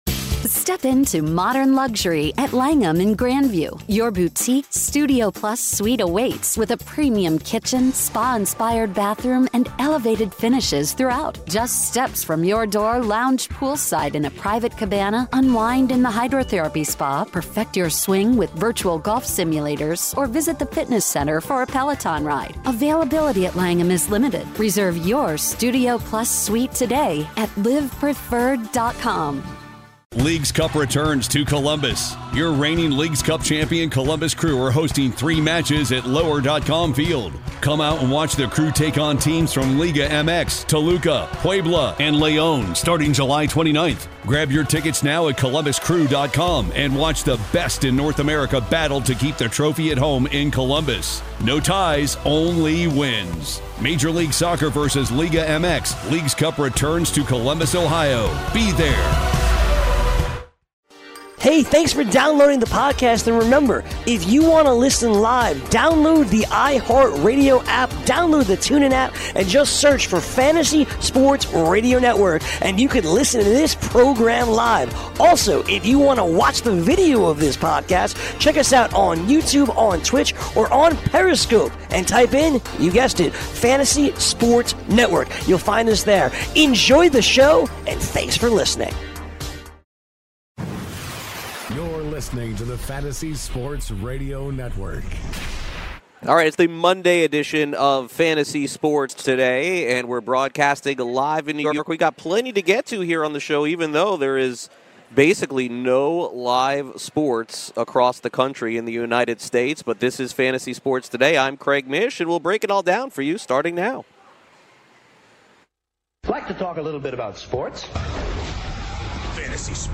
live from New York City